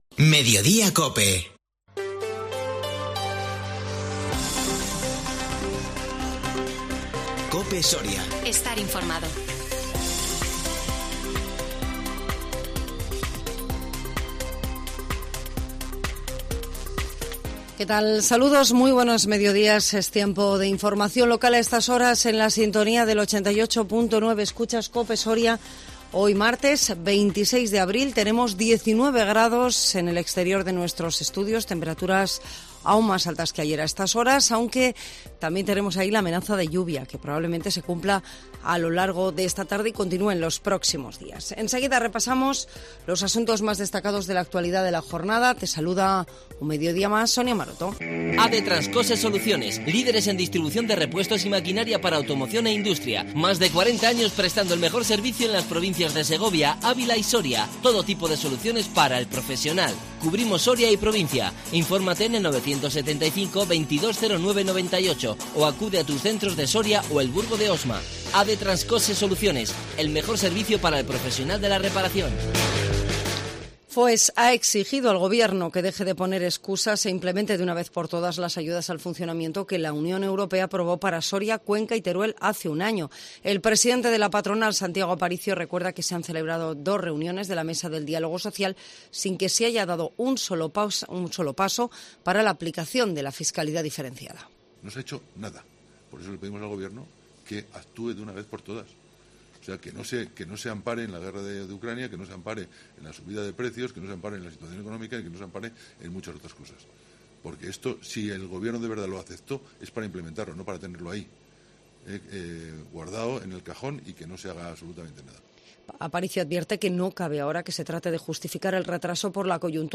INFORMATIVO MEDIODÍA COPE SORIA 26 ABRIL 2022